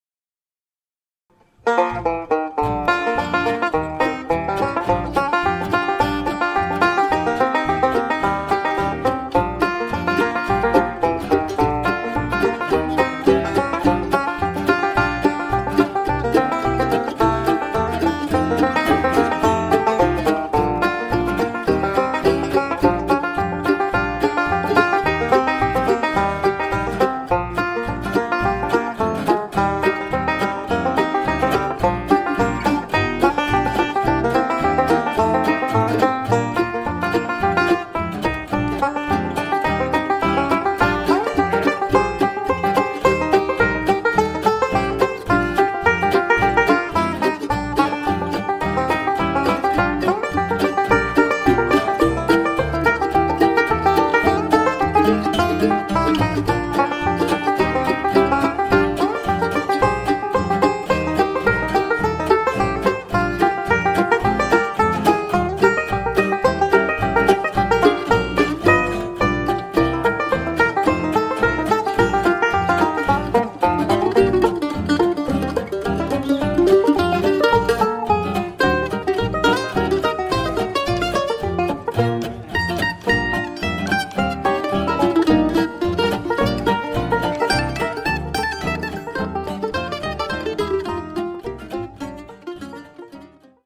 5-strin banjo in 4/4 time